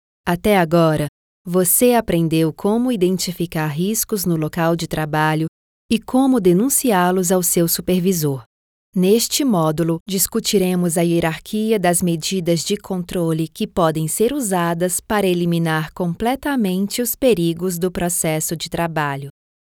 Her voice is described as versatile, friendly, conversational and her voice range goes from 12 to 35 years old.
Sprechprobe: eLearning (Muttersprache):
E-LEARNING - PERIGOS .mp3